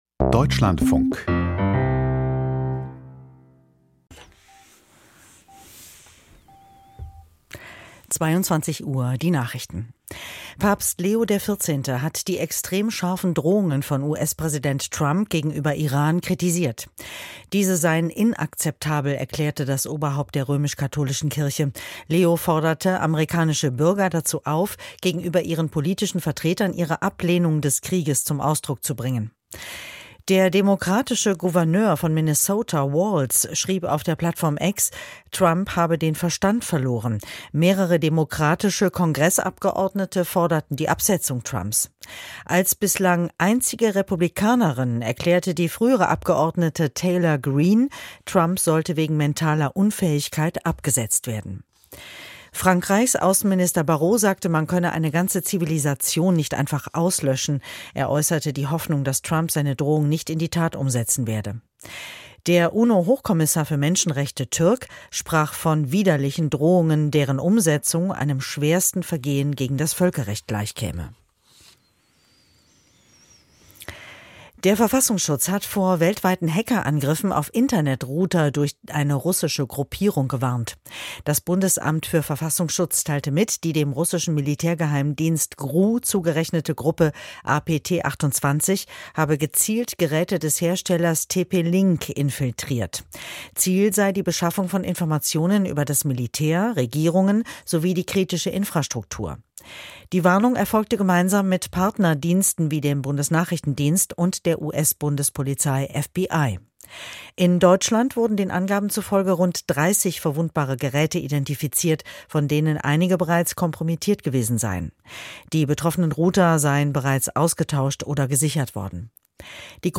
Die Nachrichten vom 07.04.2026, 21:59 Uhr
Aus der Deutschlandfunk-Nachrichtenredaktion.